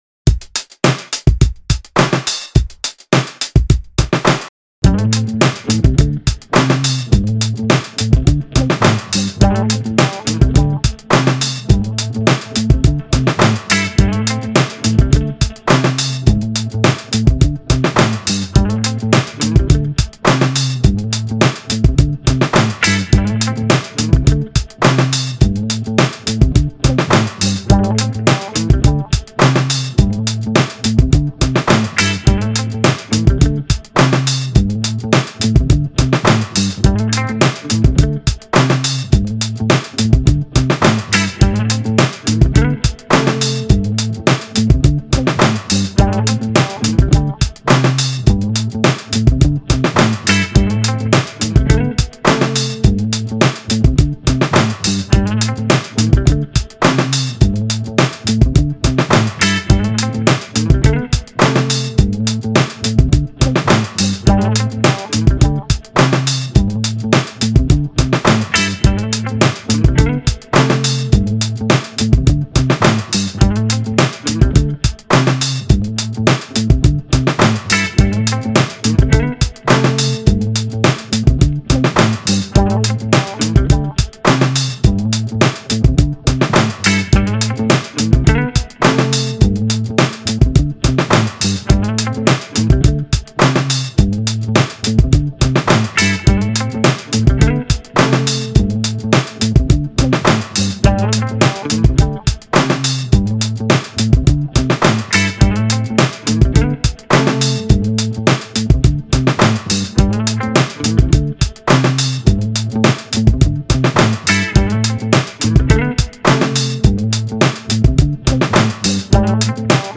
フリーBGM